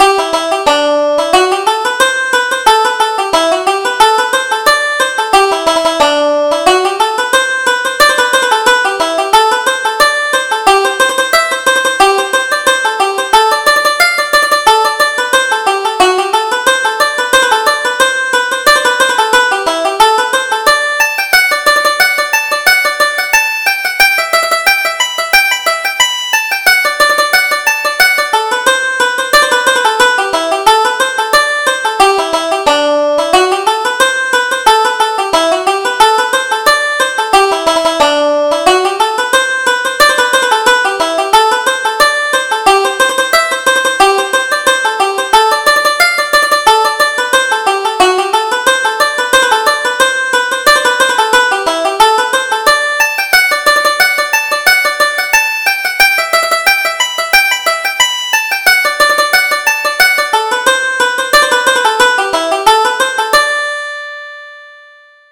Reel: Bunker Hill